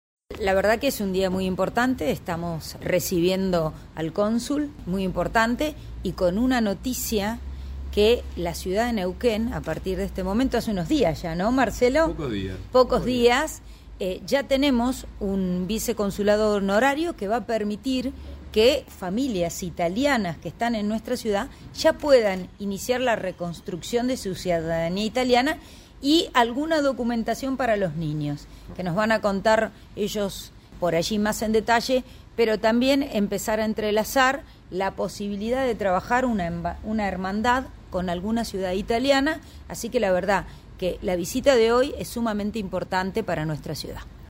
María Pasqualini, secretaria de Jefatura de Gabinete.